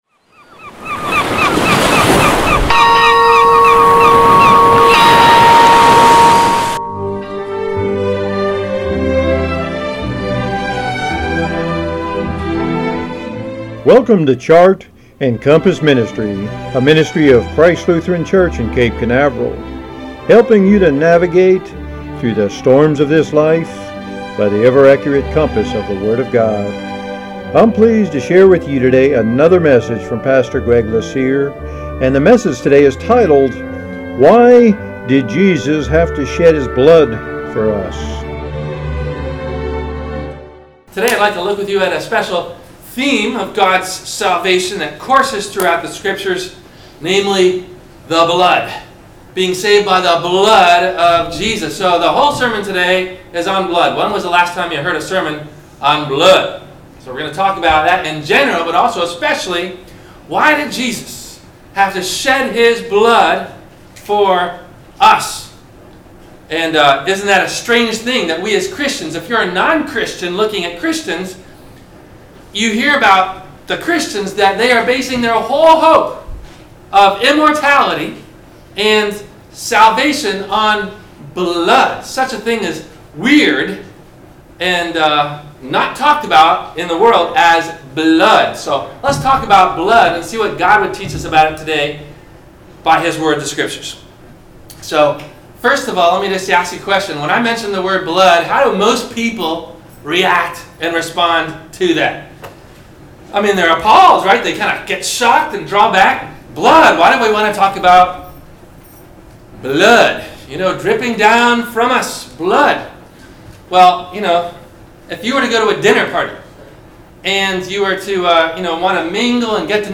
Above are Questions asked before the Message.
WMIE Radio – Christ Lutheran Church, Cape Canaveral on Mondays from 12:30 – 1:00 This Sermon originally was called “Sorcery “ , and was first shared on March 07, 2018 here.